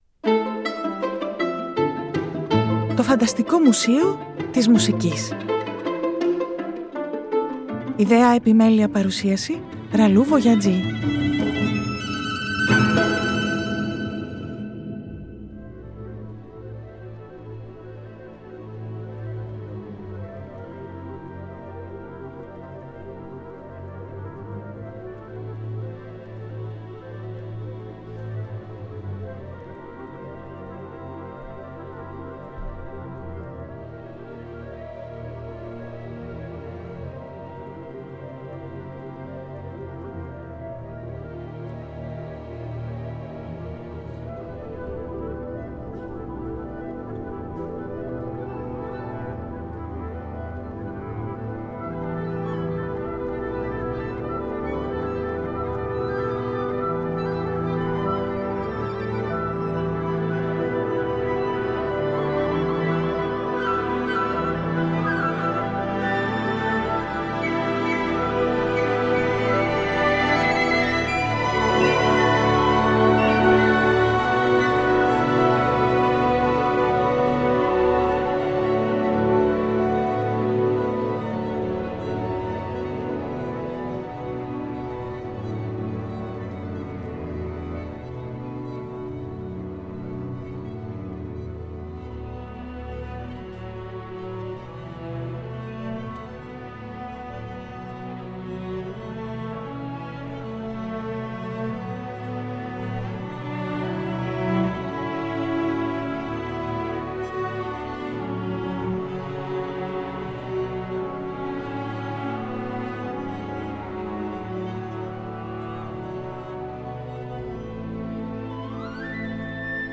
Μουσικό ξημέρωμα του 1912 σε σκηνή μπαλέτου στο Παρίσι… και οι συνειρμοί μας σε αυτή την ξενάγηση τελικά δεν θα είναι ελεύθεροι. Όλα τα βήματά μας οδηγούν σε αίθουσες του Φανταστικού Μουσείου Μουσικής όπου ο ήλιος ξημερώνει ξανά και ξανά και ηχεί μουσική από κάποια πρωινά του κόσμου.